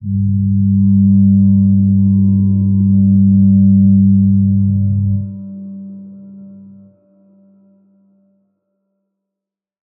G_Crystal-G3-mf.wav